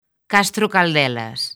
Transcrición fonética
ˈkastɾo̝kalˈdɛla̝s